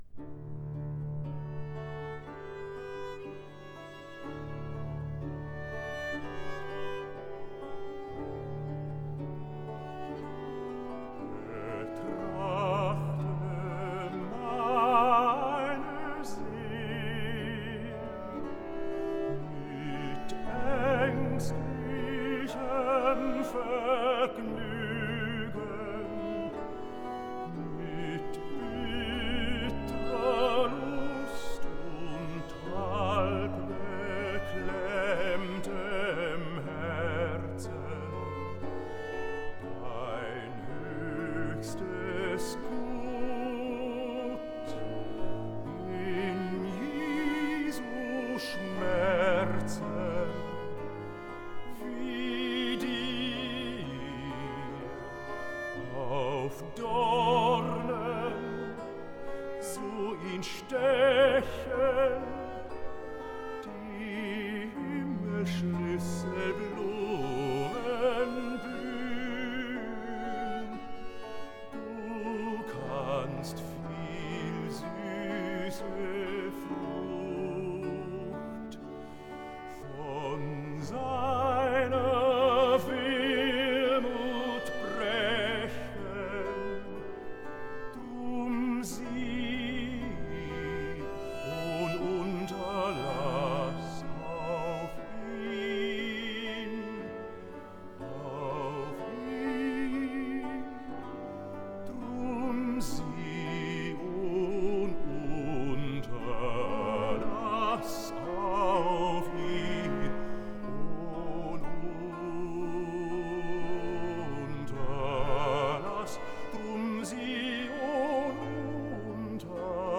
Arioso (Bass)